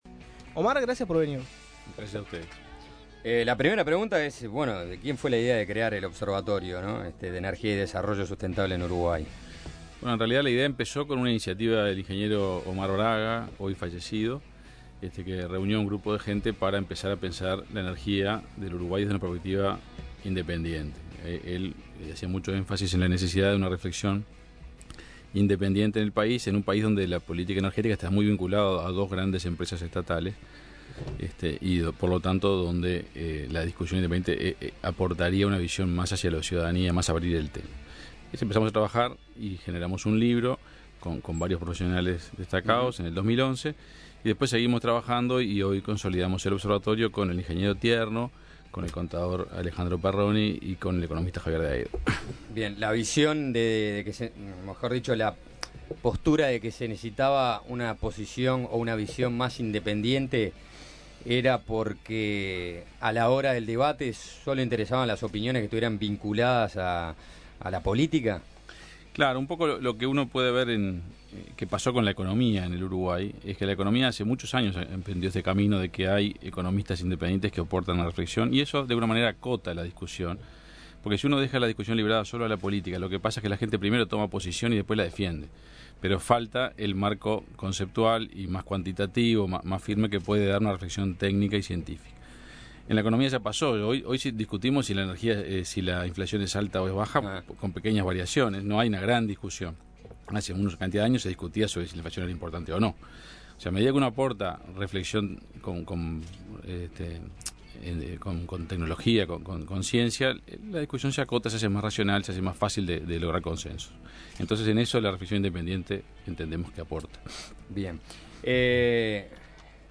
Suena Tremendo conversó con este ingeniero, que integra el Consejo Honorario del Observatorio de Energía y Desarrollo Sustentable de la Universidad Católica del Uruguay. Paganini nos contó las fortalezas y debilidades de la energía eólica, nuclear y analizó el proyecto de la planta regasificadora en Uruguay.